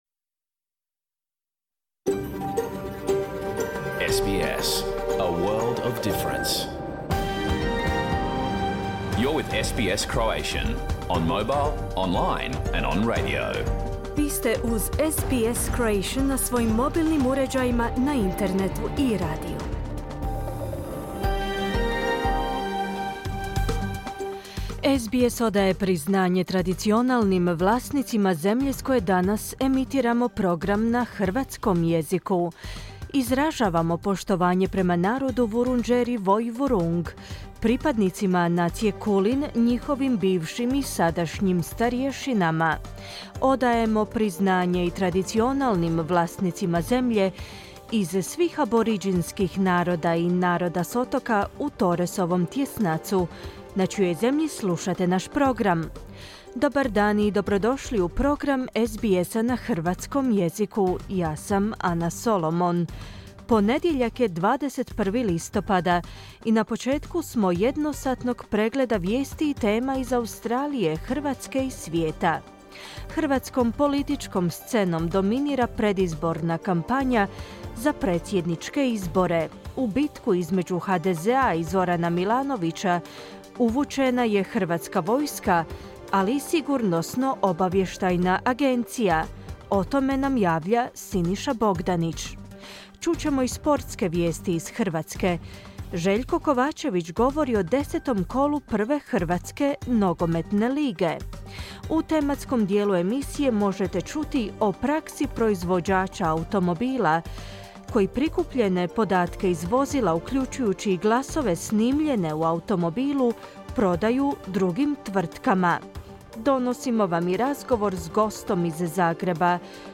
Pregled vijesti i aktualnih tema iz Australije, Hrvatske i ostatka svijeta. Emitirano uživo na radiju SBS1, u ponedjeljak, 21. listopada, s početkom u 11 sati po istočnoaustralskom vremenu.